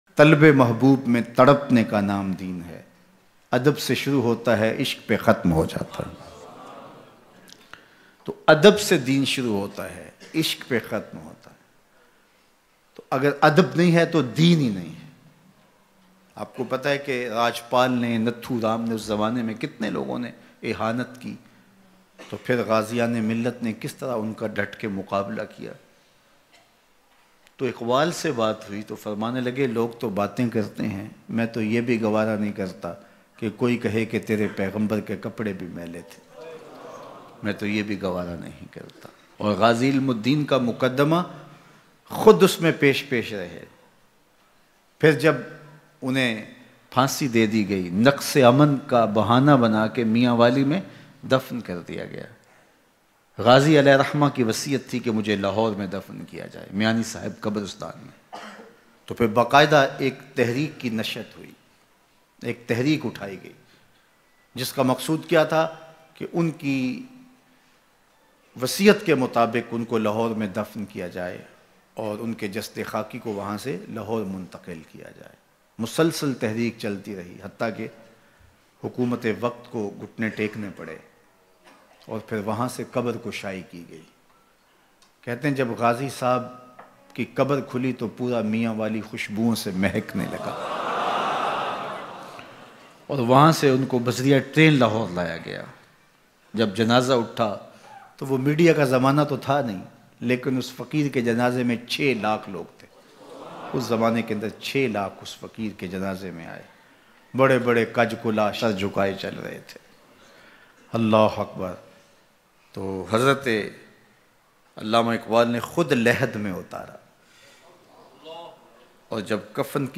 Allama Iqbal ki Ghazi ilmudin se sargoshiyaan Bayan